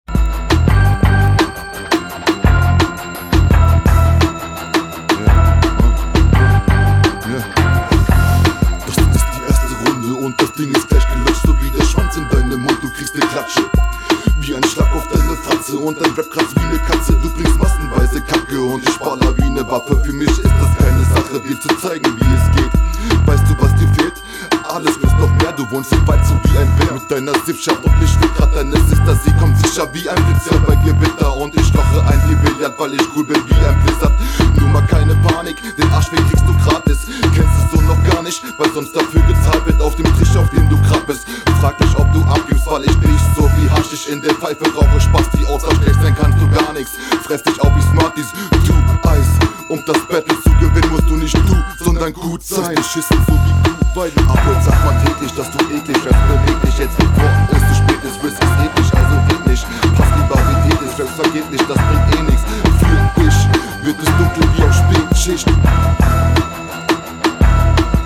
Der Beat ist Okay, nichts besonderes aber ausreichend .
Leider ist deine Abmische hier vollkommene Katastrophe.